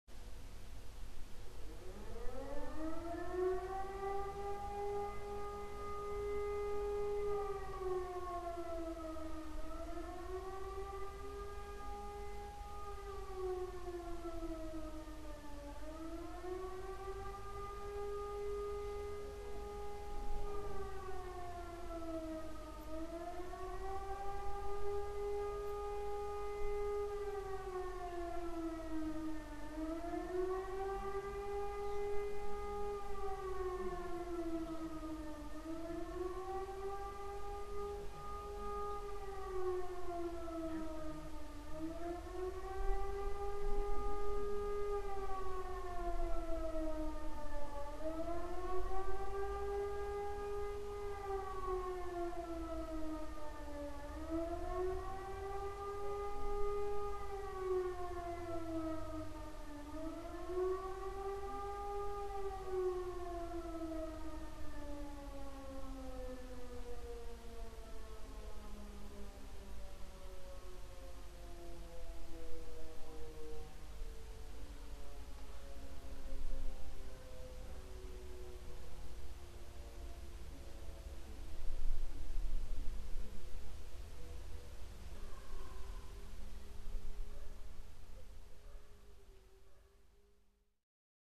bomb raid alarm in Belgrade
BombRaid.mp3